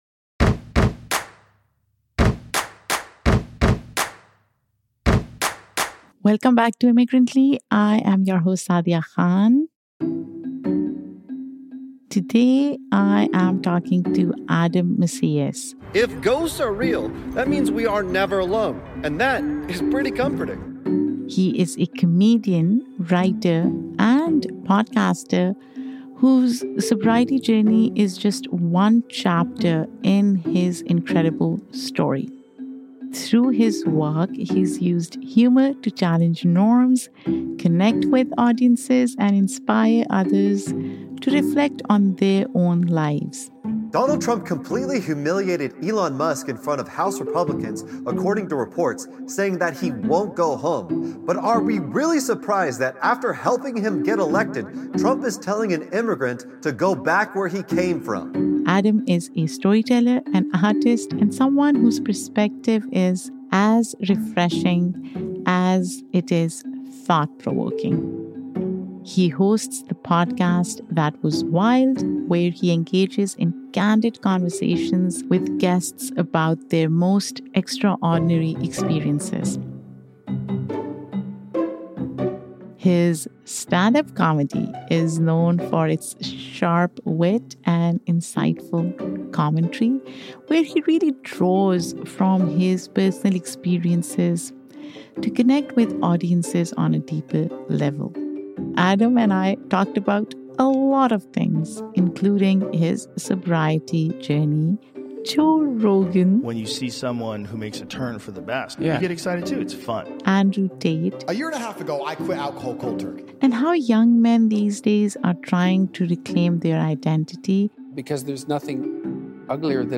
This episode is full of laughter, reflection, and a little bit of feline love—don’t miss it!